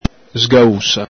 Alto Vic.